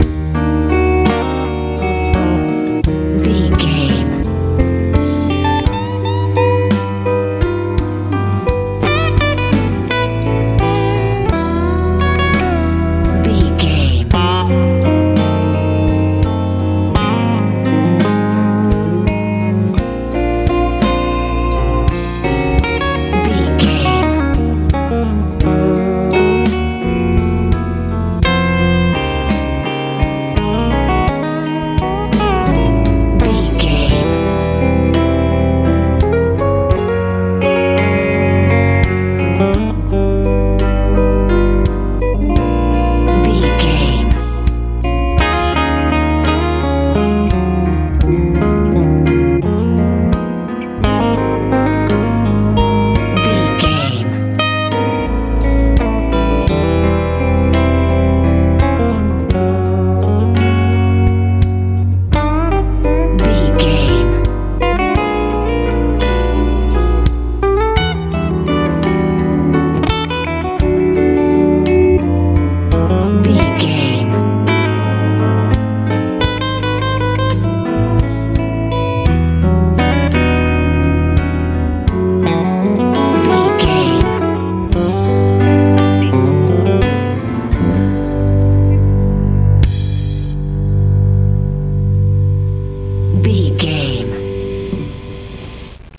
Ionian/Major
C♭
calm
mellow
acoustic guitar
electric guitar
piano
bass guitar
drums
relaxed
happy